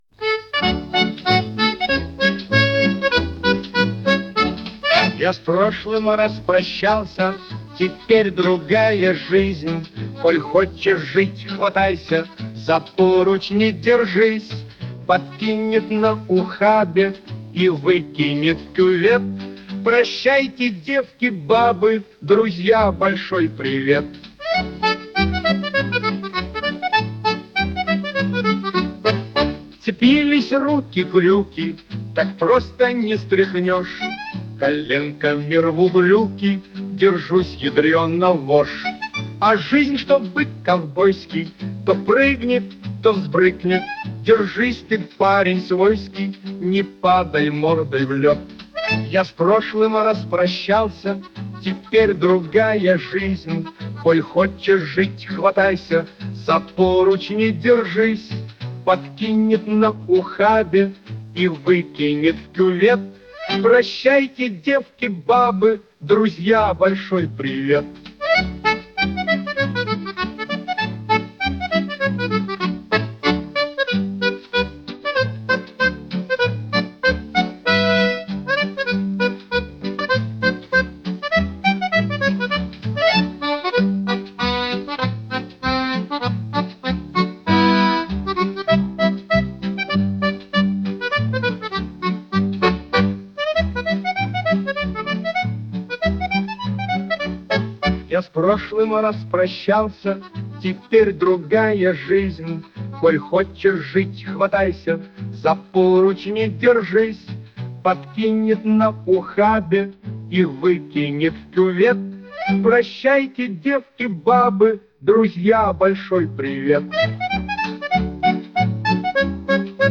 стиль 50-х гг.